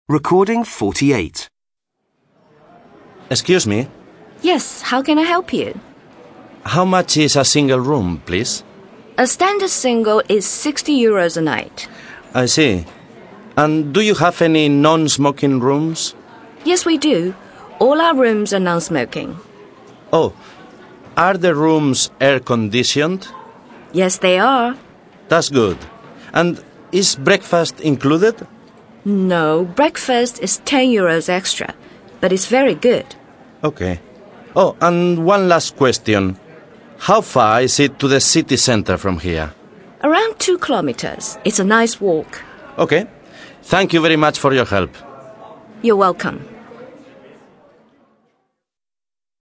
He goes into a hotel in Berlin to book a room. Listen to the cnversation and complete each question he asks.